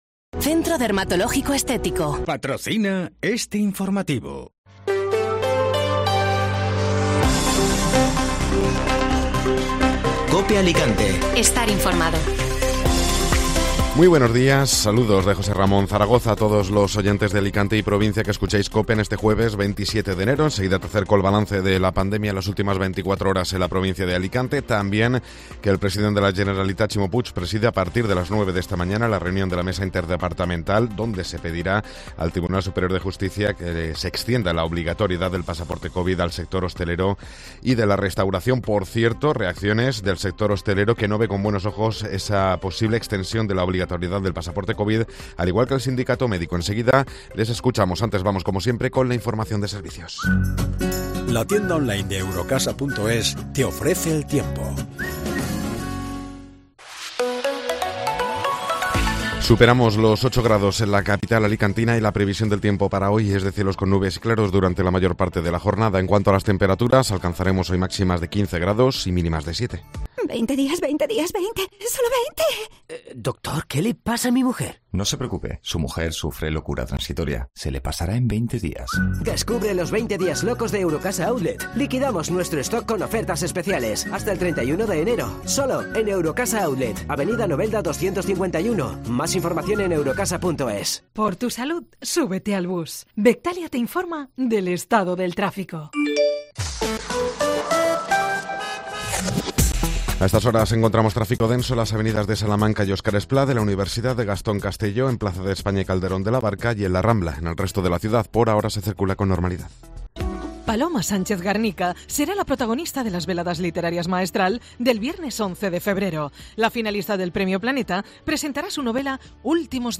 Informativo Matinal (Jueves 27 de Enero)